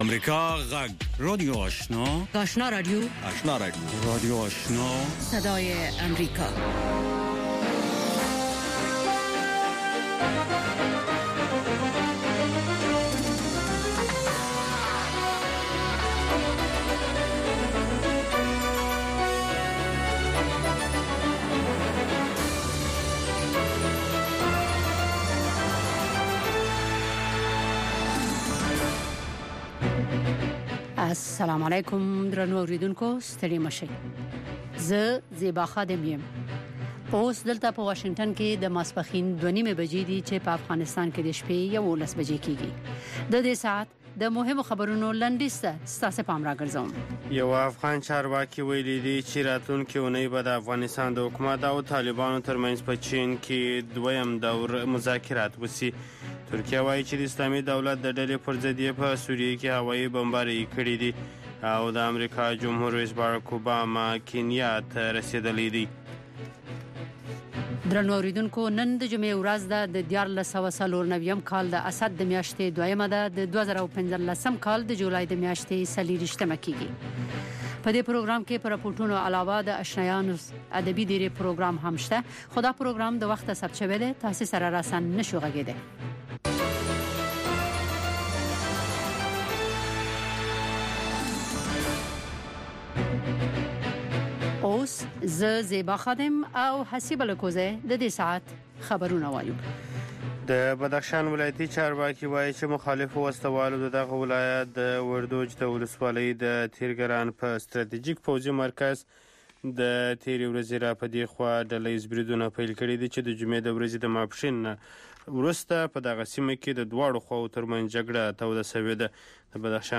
یو ساعته پروگرام: تازه خبرونه، او د نن شپې تېر شوي پروگرامونه ثبت شوي او بیا خپریږي چې د شعر او ادب په گډون هنري، علمي او ادبي مسایل رانغاړي.